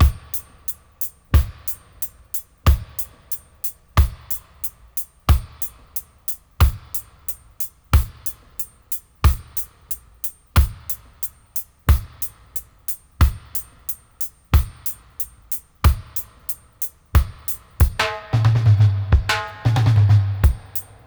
90-FX-03.wav